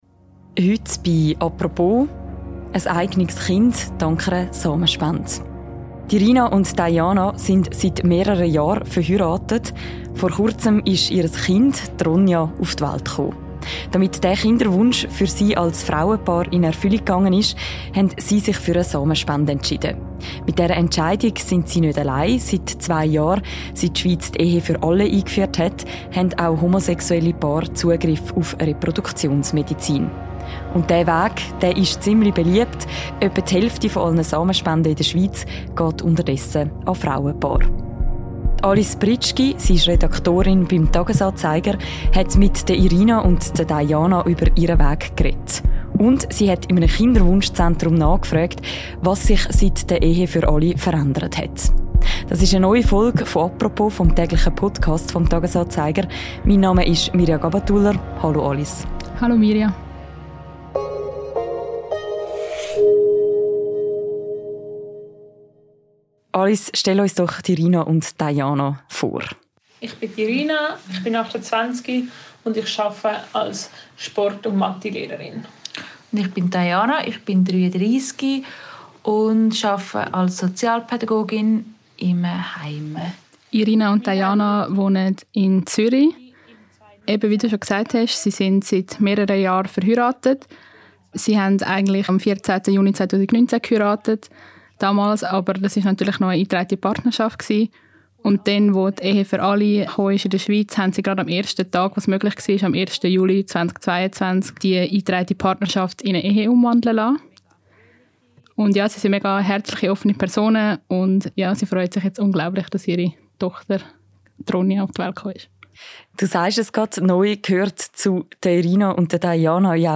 Zwei Mütter erzählen.